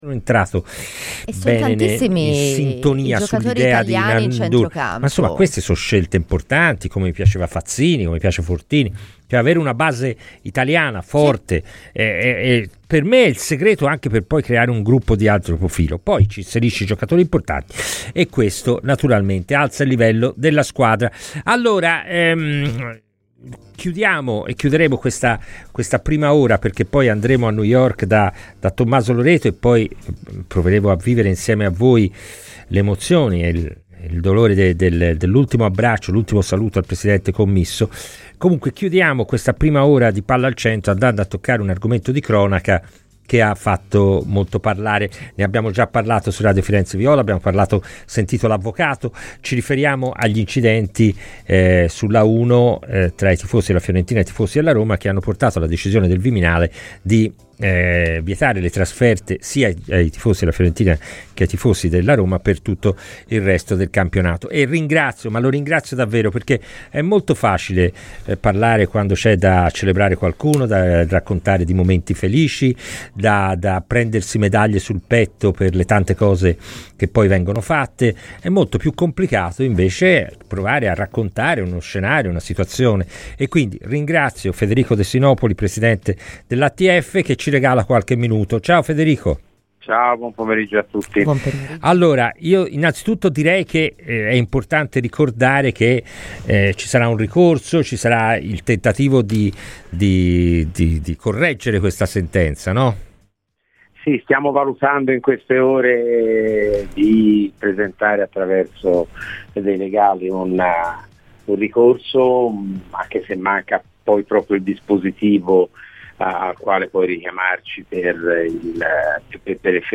è intervenuto ai microfoni di Radio FirenzeViola nel corso di " Palla al Centro " per parlare degli scontri avvenuti sull' A1 tra i tifosi viola e romanisti